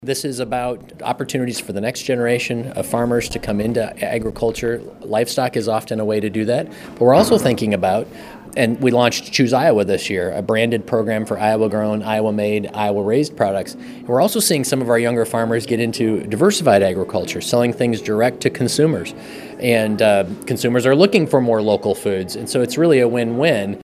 Naig describes what’s behind these trends.